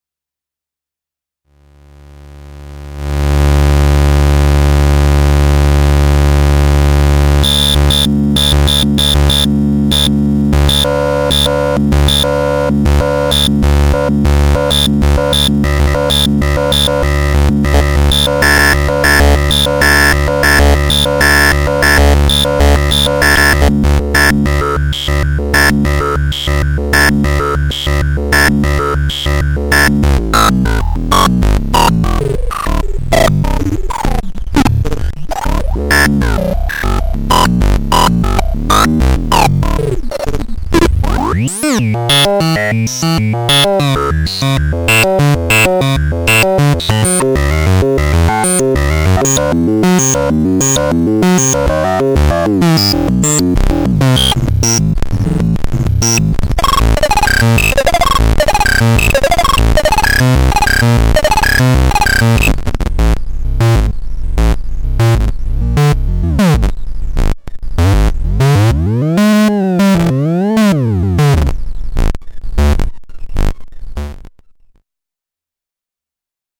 53_gated-comp.mp3